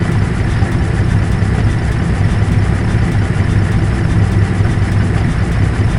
generator_gas.wav